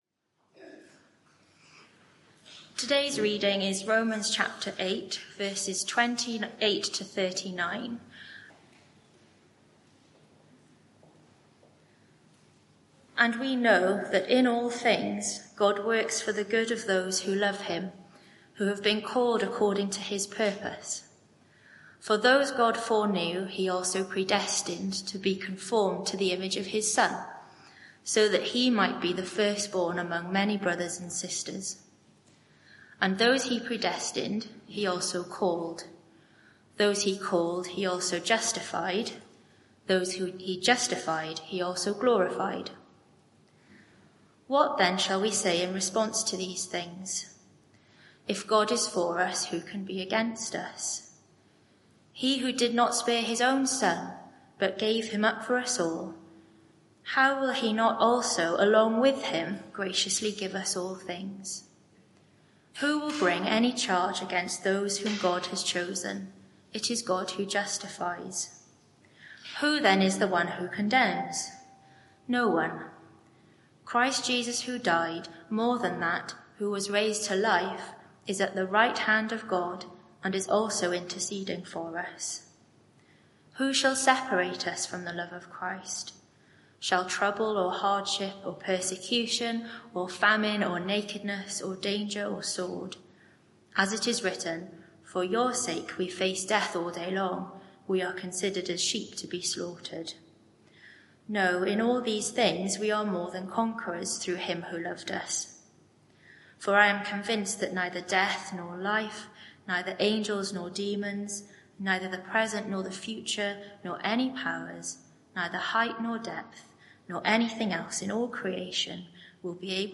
Media for 11am Service on Sun 22nd Sep 2024 11:00 Speaker
Sermon (audio)